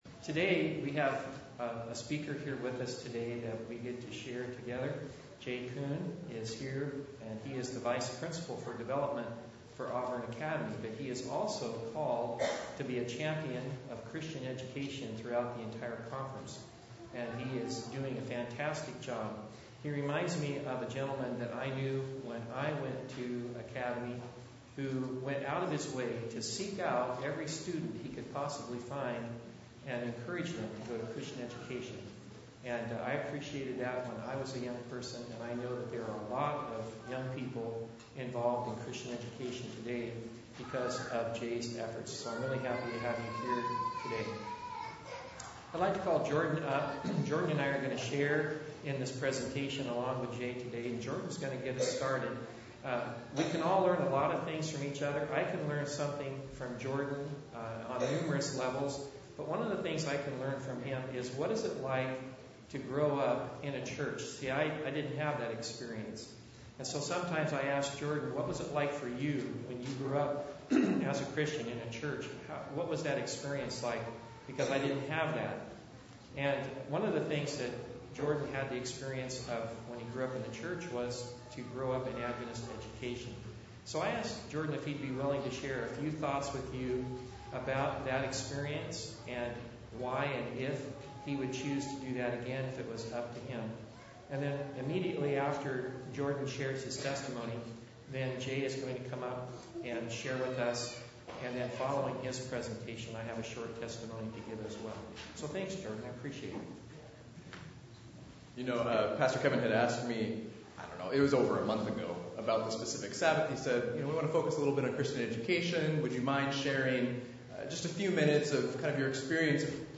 Service Type: Sabbath